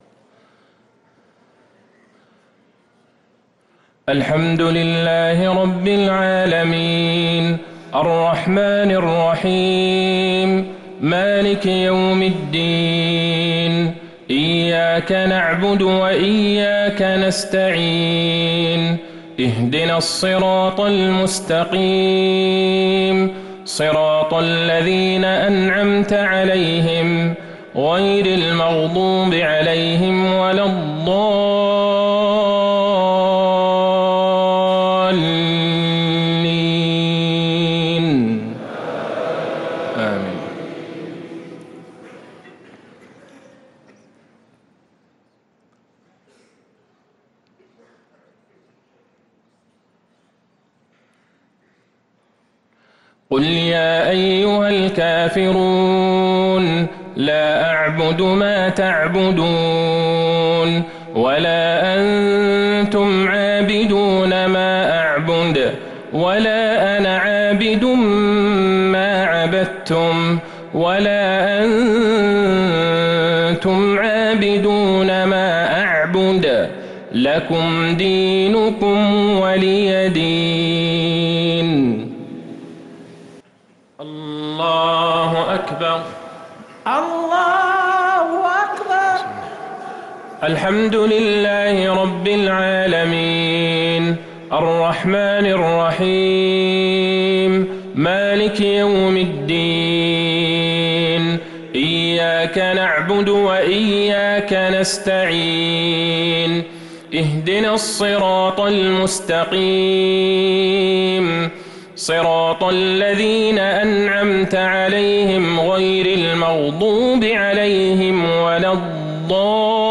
صلاة المغرب للقارئ عبدالله البعيجان 15 ذو الحجة 1444 هـ
تِلَاوَات الْحَرَمَيْن .